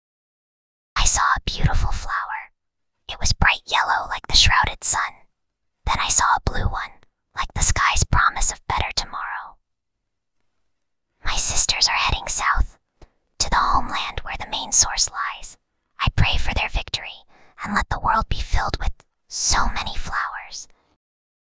Whispering_Girl_23.mp3